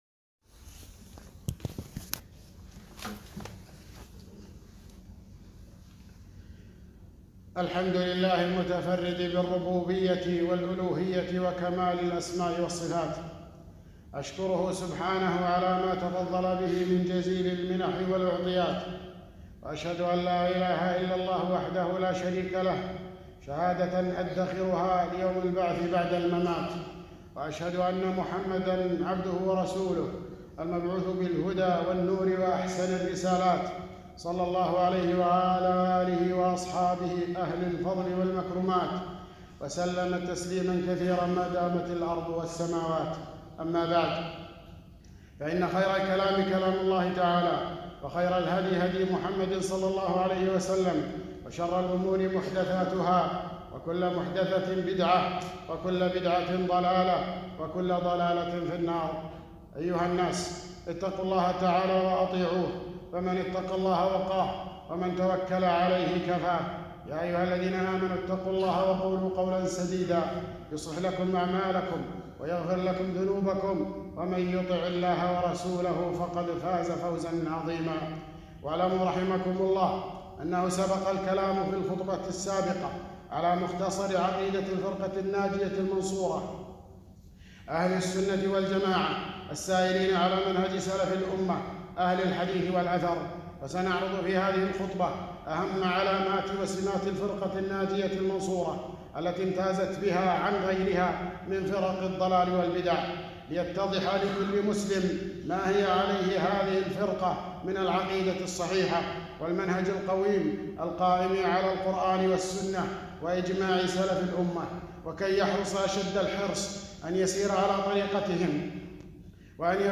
خطبة - سمات الفرقة الناجية المنصورة